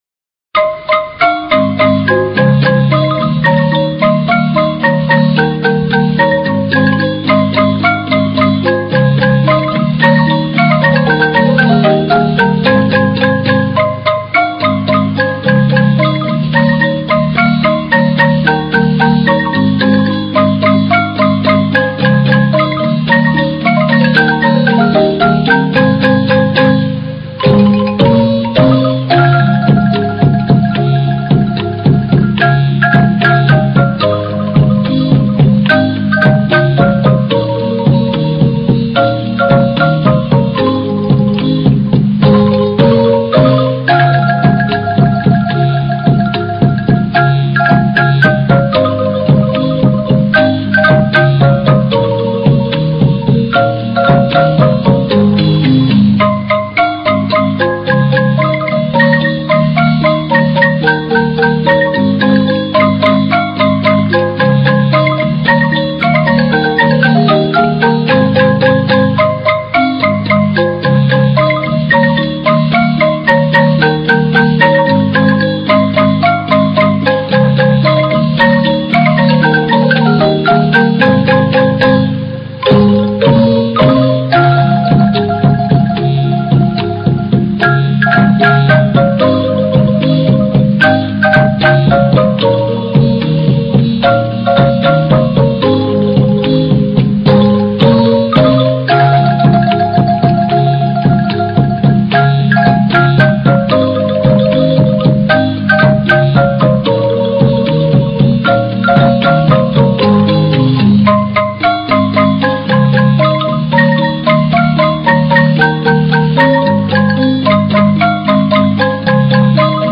Suonatori di marimba
Marimba
marimba.ram